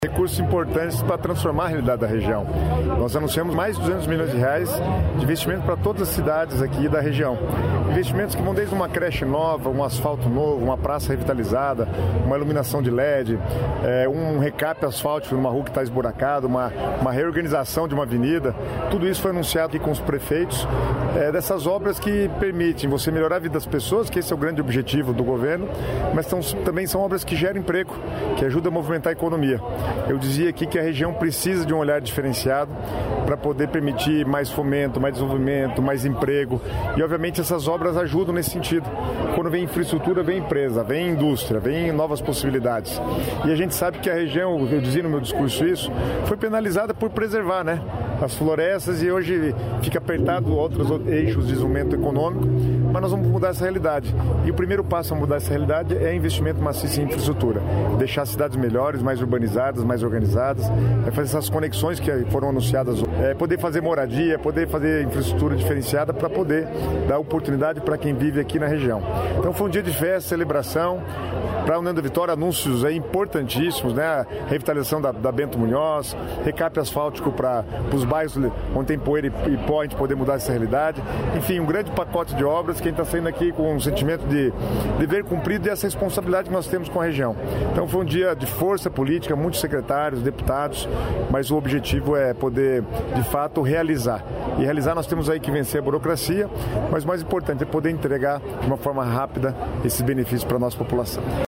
Sonora do secretário das Cidades, Guto Silva, sobre licitações que somam R$ 214 milhões para municípios da Região Sul